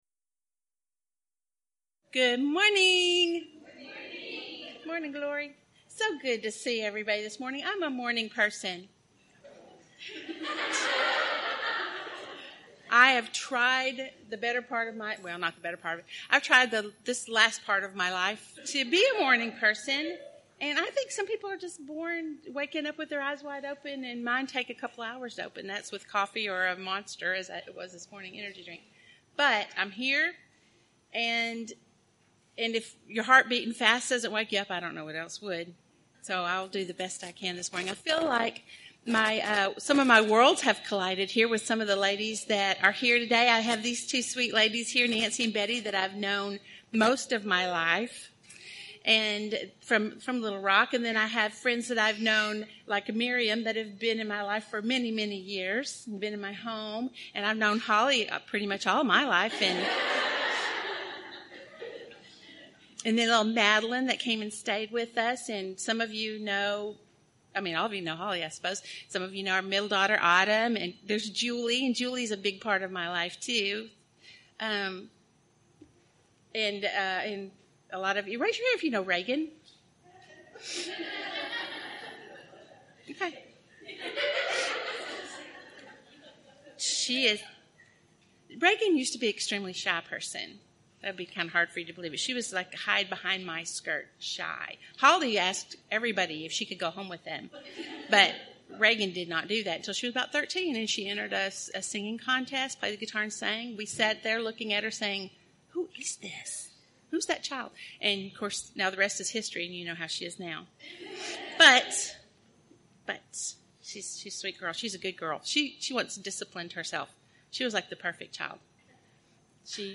Event: 1st Annual Women of Valor Retreat
Ladies Sessions